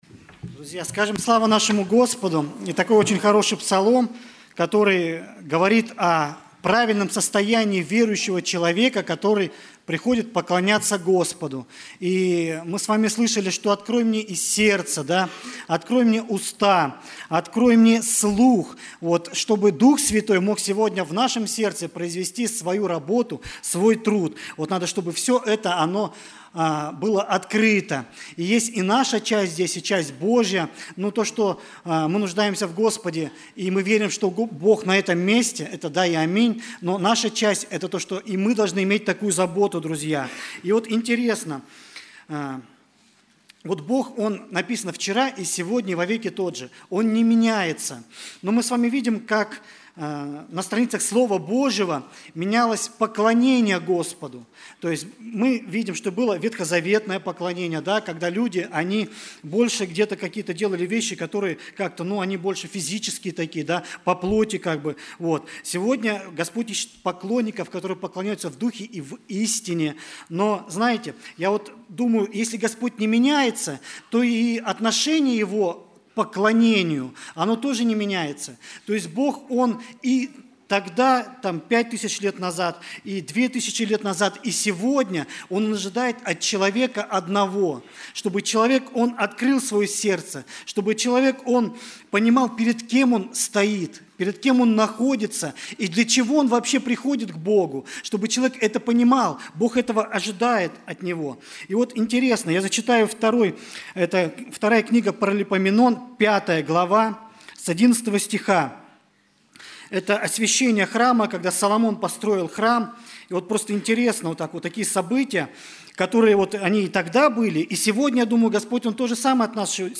Богослужение 28.09.2024
Проповедь